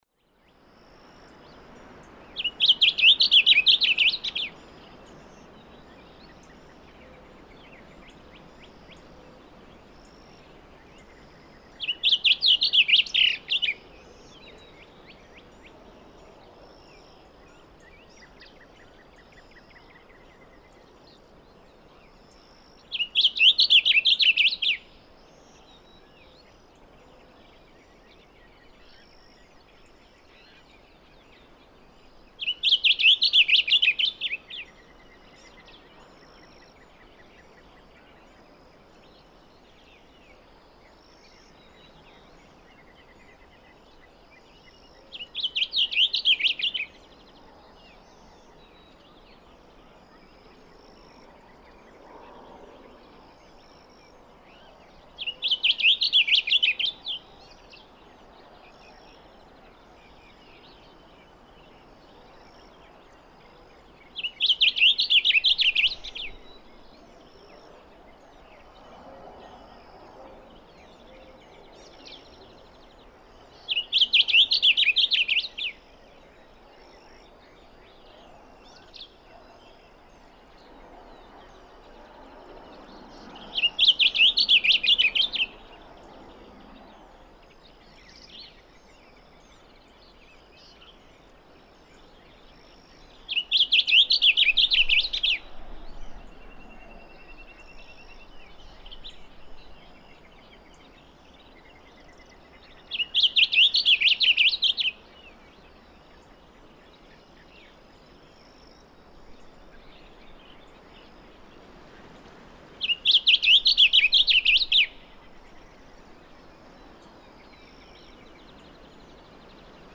Blue grosbeak
I set the microphone down by this male's singing tree and came back hours later to discover that he had in fact obliged. At 19:20 the recording fades out and then back in, denoting a silence from this bird of about 50 minutes.
Kerr Wildlife Management Area, Hunt, Texas.
695_Blue_Grosbeak.mp3